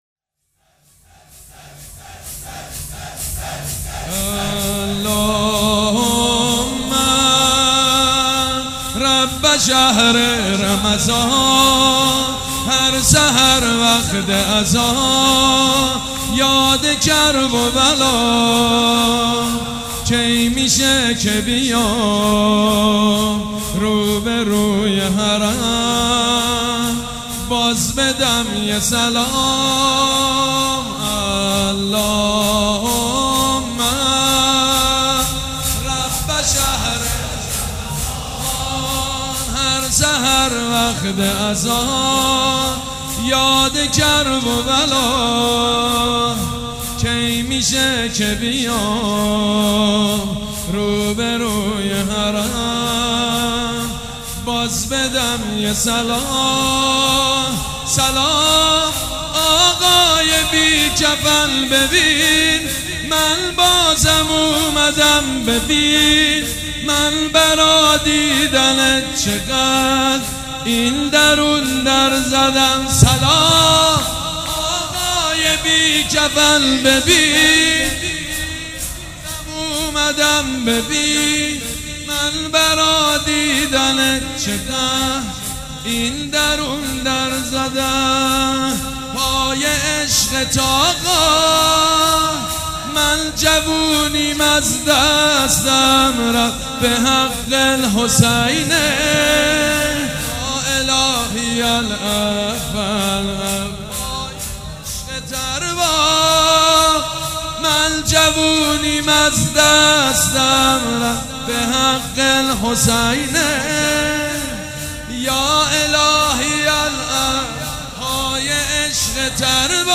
گلچین مداحی ماه رمضان با صدای حاج سید مجید بنی فاطمه